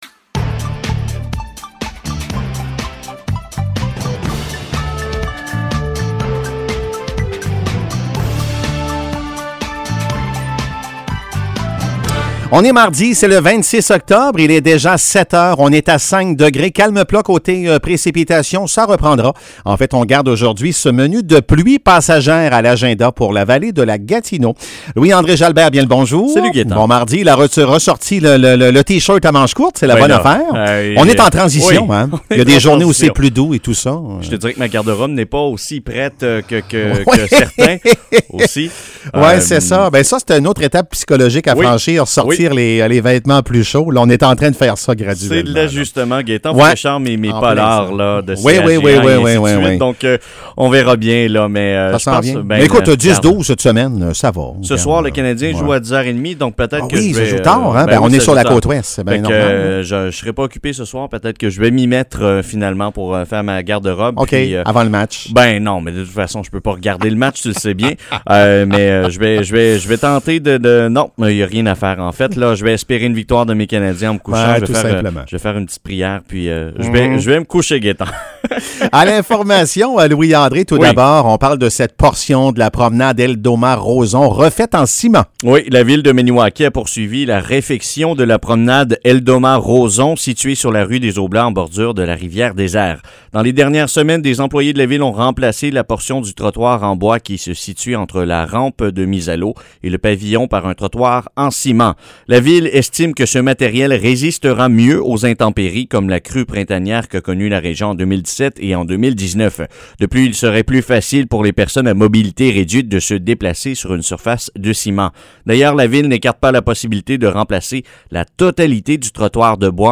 Nouvelles locales - 26 octobre 2021 - 7 h